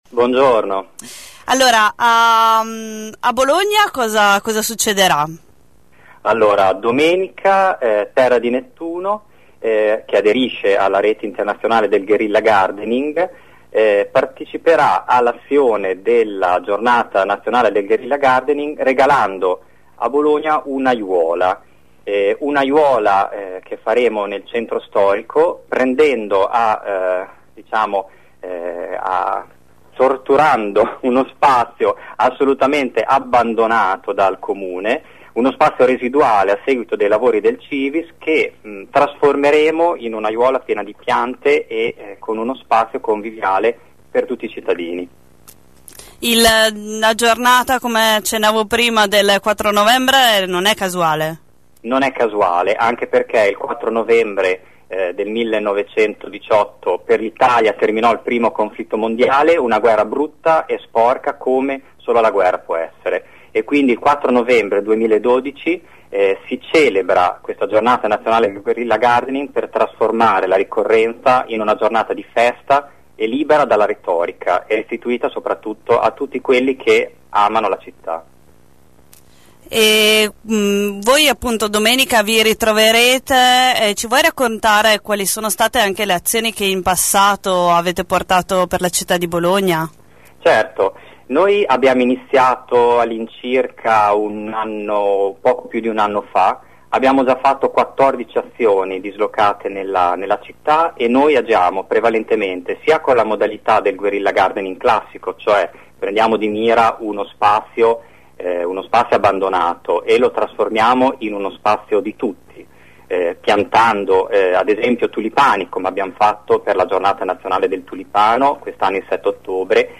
Nella giornata internazionale dedicata alle azioni verdi, l'associazione Terra Di Nettuno regalerà una nuova aiuola alla città di Bologna, all'intersezione tra via Zamboni e via Irnerio. Ascolta l'intervista